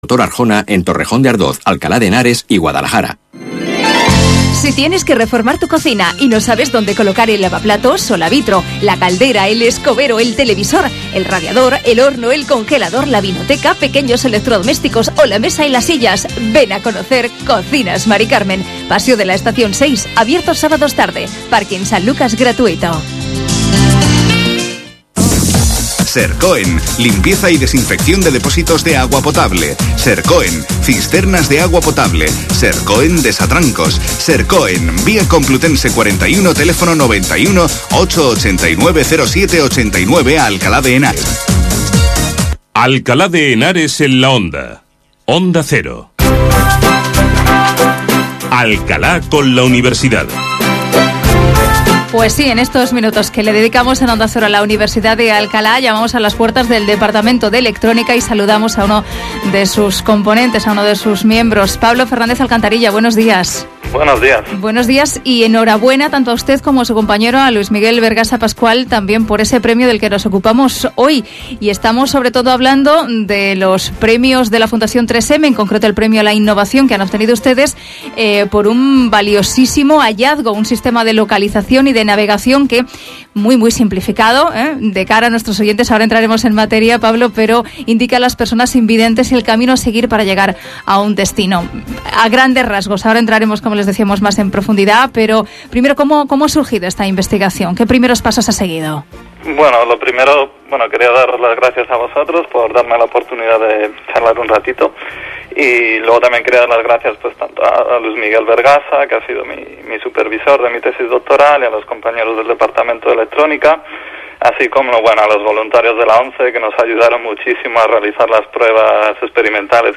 Interview in the Spanish radio Onda Cero , about the 3M Innovation Awards 2011 thanks to my thesis work about visual SLAM for assisting visually impaired users.